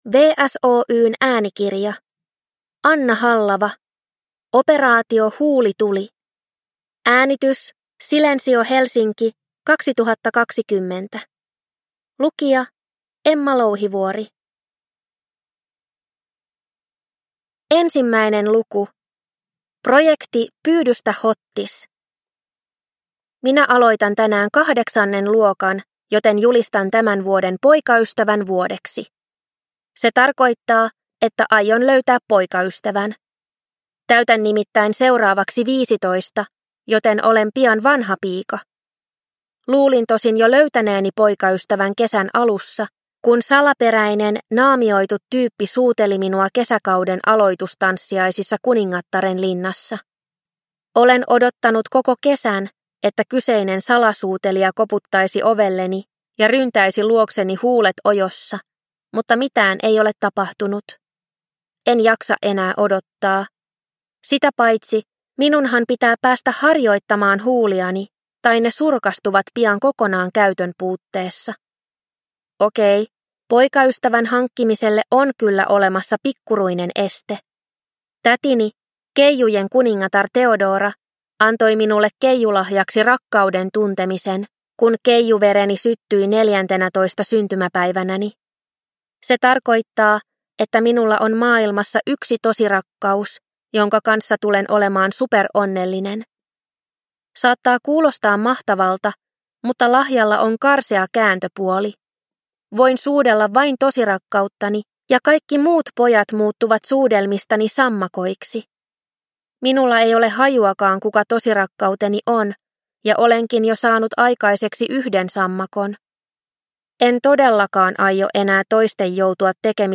Operaatio Huulituli – Ljudbok – Laddas ner